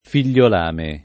figliolame [ fil’l’ol # me ] s. m.